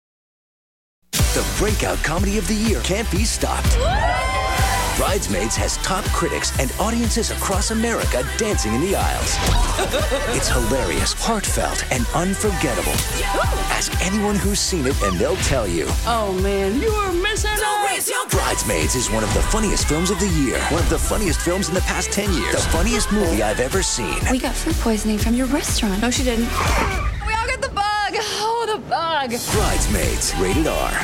TV Spots